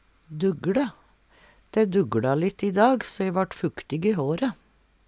duggLe - Numedalsmål (en-US)